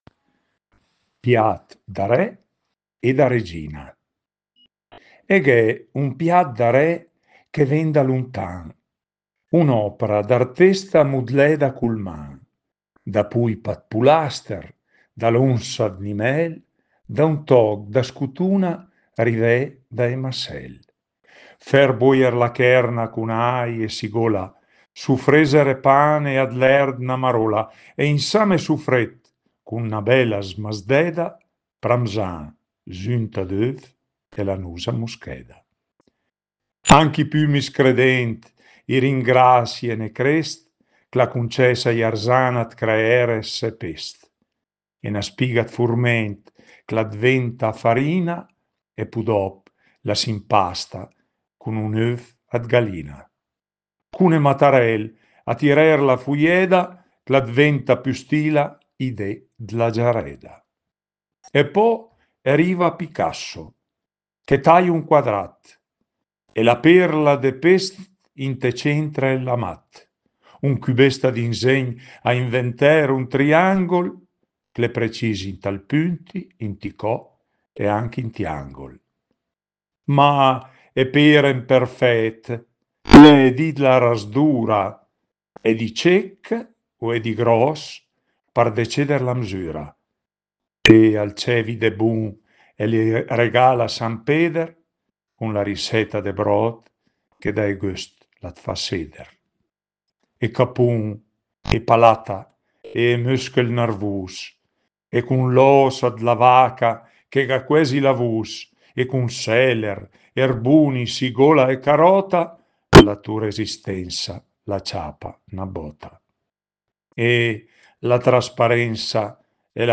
La poesia è letta dall’autore